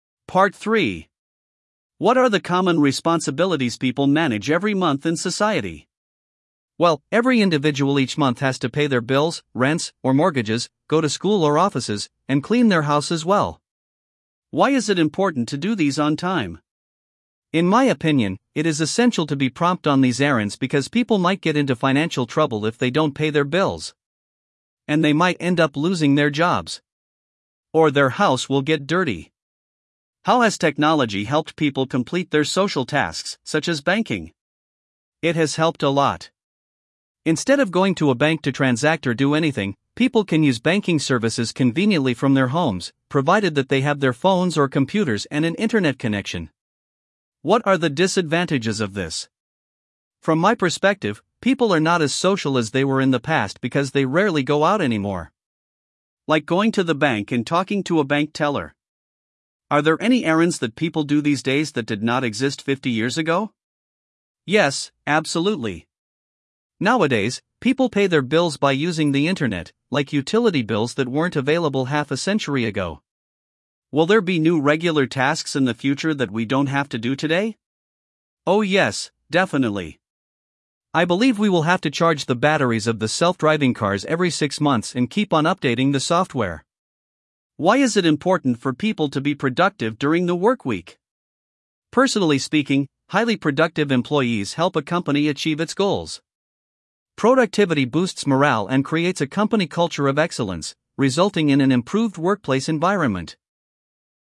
Guy (English US)